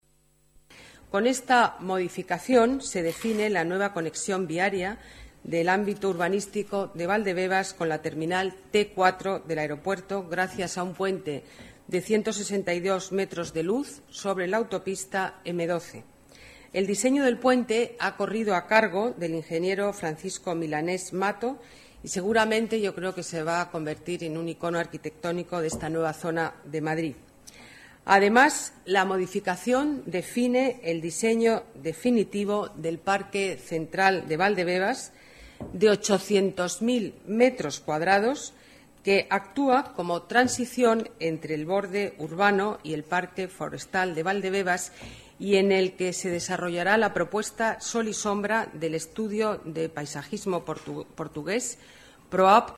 Nueva ventana:Declaraciones alcaldesa de Madrid, Ana Botella: nuevo puente en Valdebebas